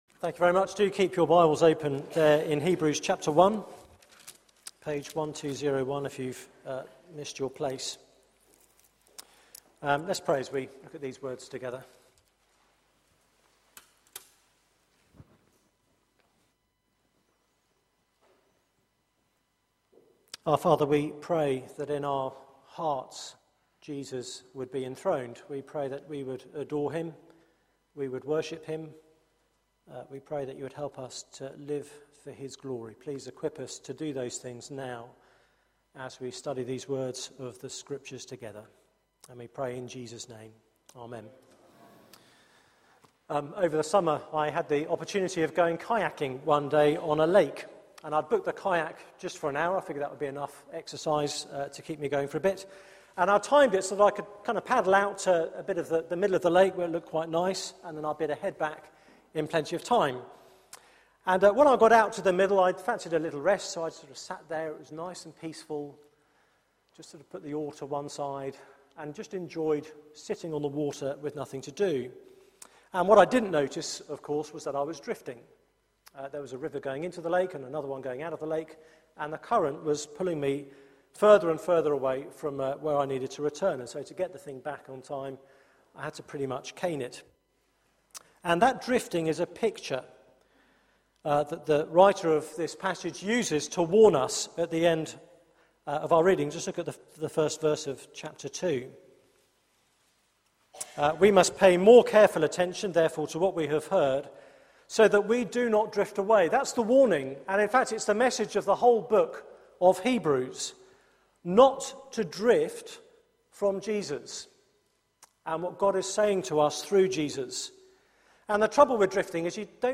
Media for 6:30pm Service on Sun 15th Sep 2013 18:30 Speaker
Theme: The man you can't ignore Sermon